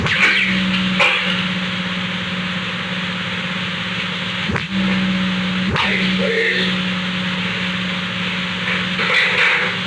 Secret White House Tapes
• President Richard M. Nixon
• White House operator
Location: Executive Office Building
The President talked with the White House operator.